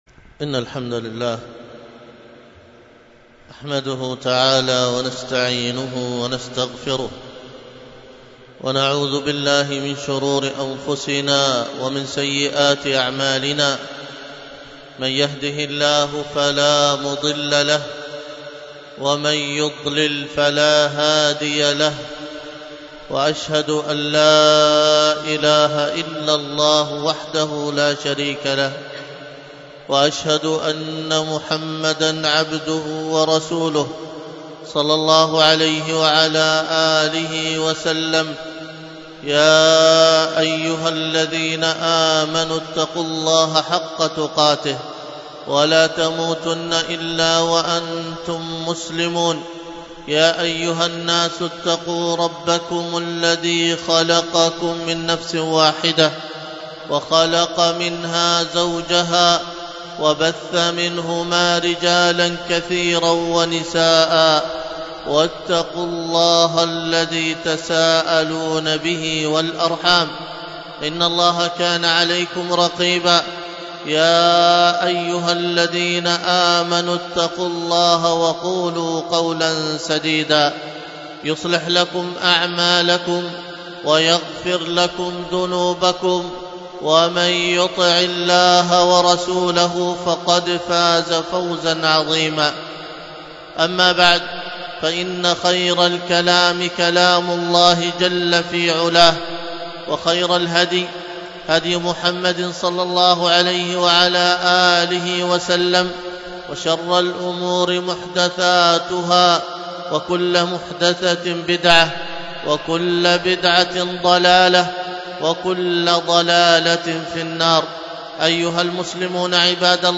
الخطبة بعنوان موت العلماء ثلمة في الإسلام، والتي كانت بمسجد السنة بدار الحديث بطيبة